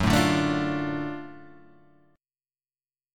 GbmM7#5 chord